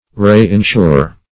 Reinsure \Re`in*sure"\ (-sh?r"), v. t.